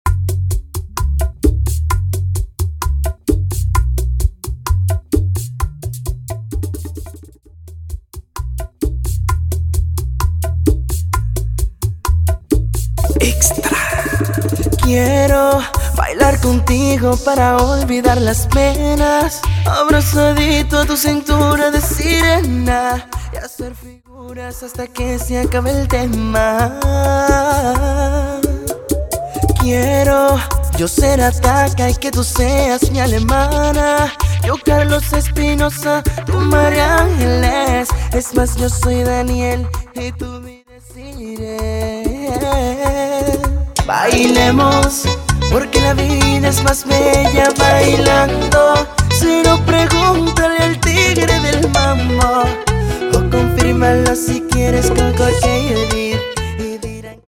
Latin genres